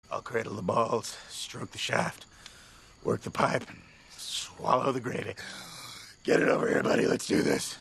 tip-alert_e427pJL.mp3